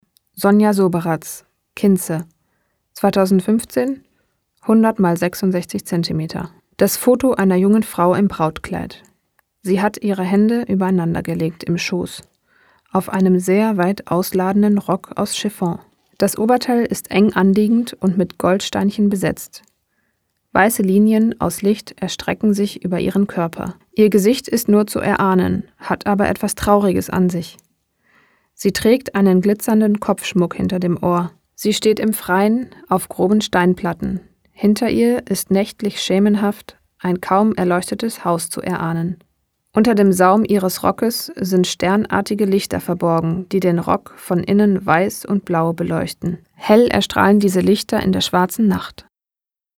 Dieses Bild wurde im Rahmen einer Ausstellung zur Filmpremiere des Dokumentarfilms „Shot in the Dark“ in der Brotfabrik Berlin gezeigt. Der Text stammt aus dem großartigen Audio Guide zur Ausstellung.